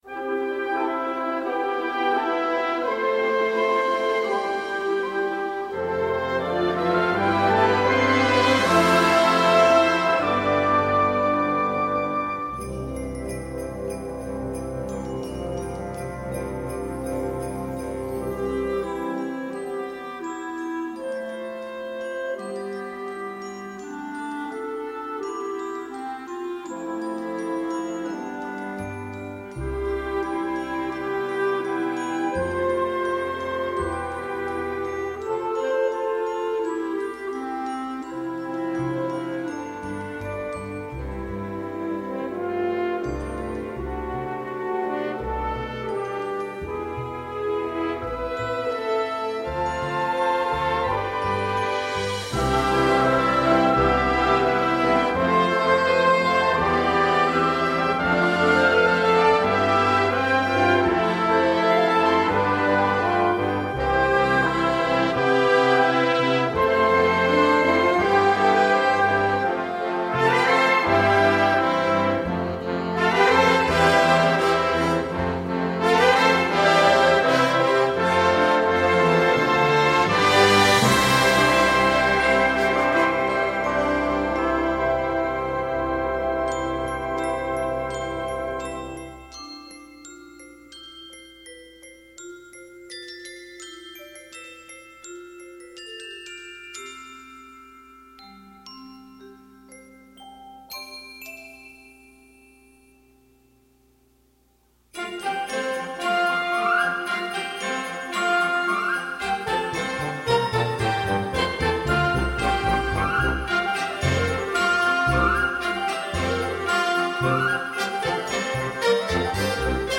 Concert de noël 2019
Pour notre traditionnel concert de noël, nous aurons le plaisir d'interpreter une musique originale de John Williams :A Home Alone Christmas